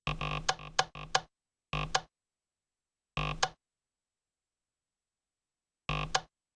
When you step on any of the 4 not activated pressure plates it will spit out a ton of that pressure plate you're on, slow down minecraft and make some weird noise.
MC debug mode pressure plate failure sound.ogg